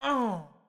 SFX_Mavka_Hit_Voice_08.wav